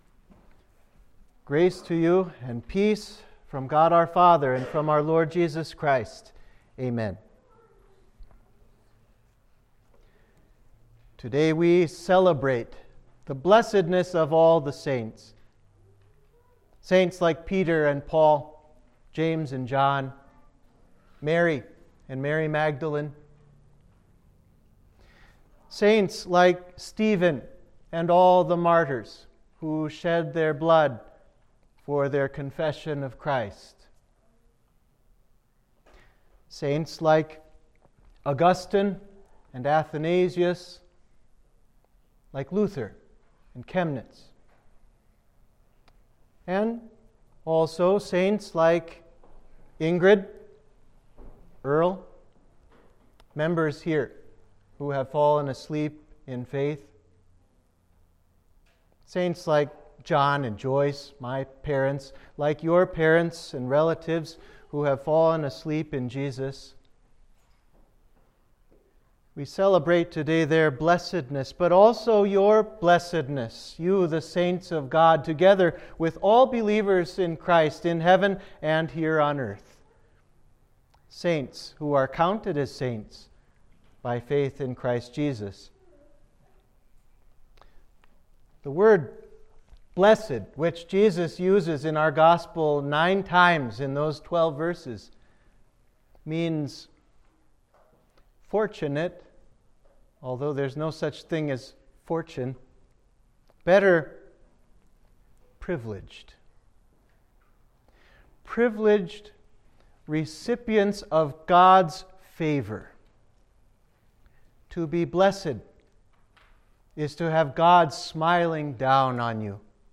Sermon for All Saints’ Day